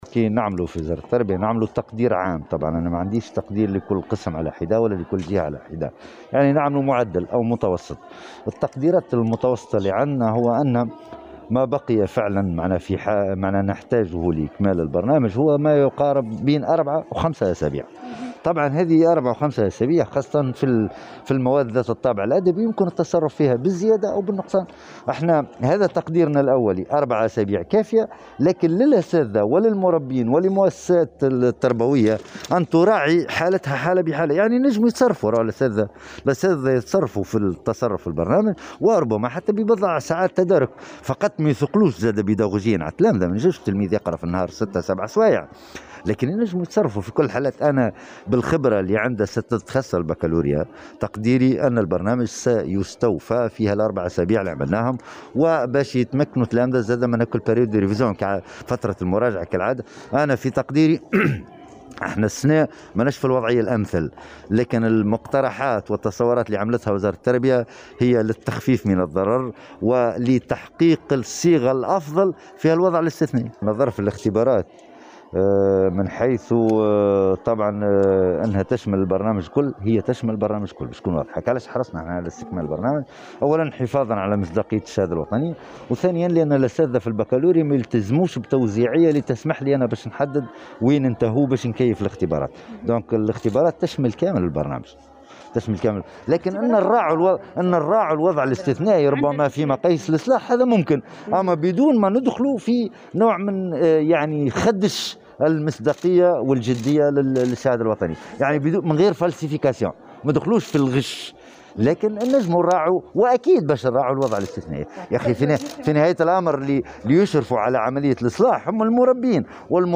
وأكد الحامدي على هامش زيارته للمعهد الثانوي المرسى الرياض، أن الأسابيع الـ4 القادمة كافية لاستكمال البرنامج البيداغوجي، مشيرا إلى أن الامتحانات ستشمل كافة البرنامج وذلك حفاظا على مصداقية شهادة البكالوريا.